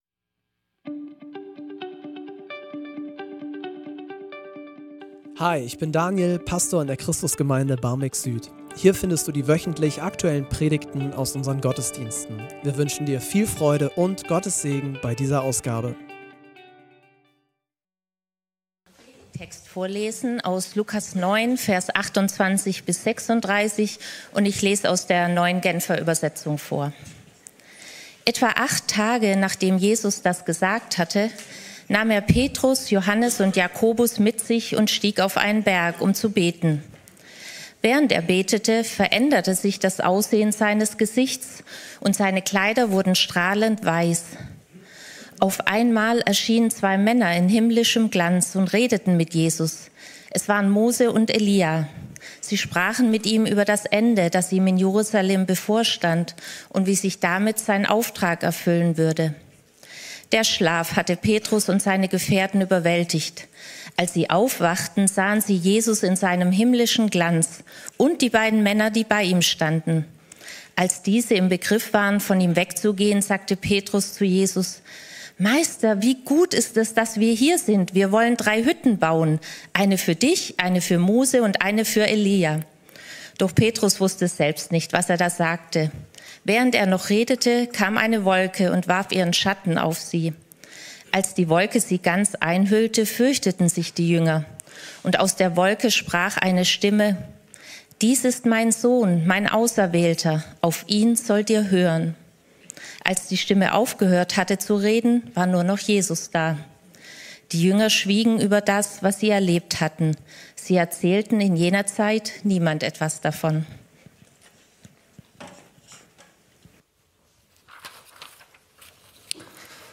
Gemeinsam unterwegs - Interviewpredigt.